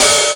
Index of /90_sSampleCDs/Classic_Chicago_House/Drum kits/kit05
cch_06_hat_open_mid_loose_nasty.wav